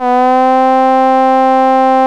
VOICE C3 S.wav